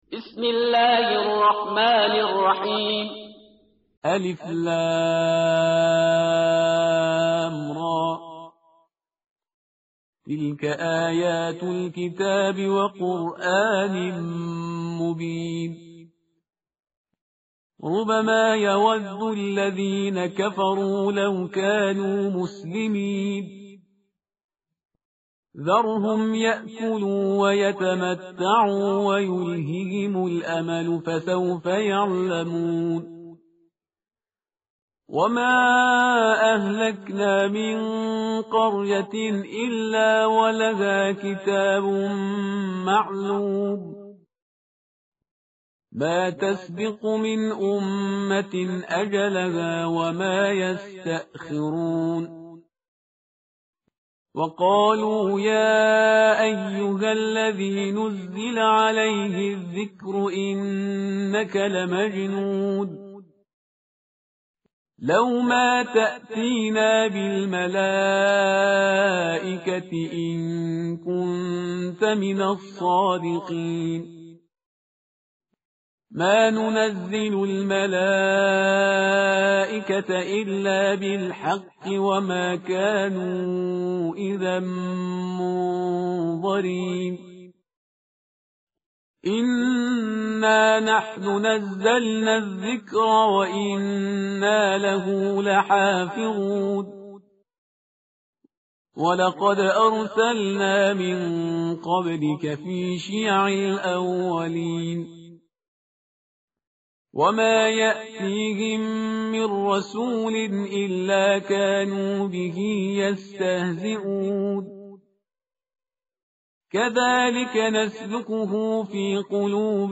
tartil_parhizgar_page_262.mp3